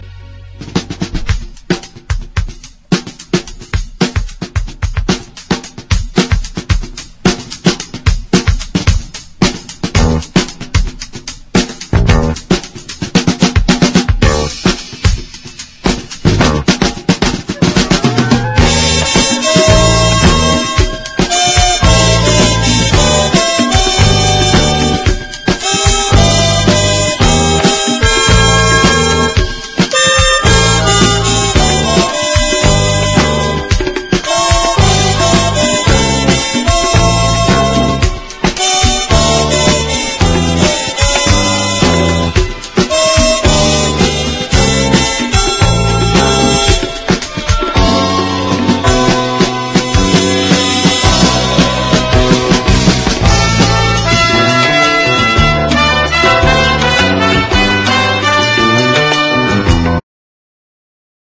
ROCK / 70'S / GERMAN EXPERIMENTAL / KRAUT ROCK / AMBIENT
テクノの祖にしてジャーマン･エクスペリメンタル傑作！
タイトに転がりまわる小気味いいミニマル・ビートでスピリチュアル感たっぷりの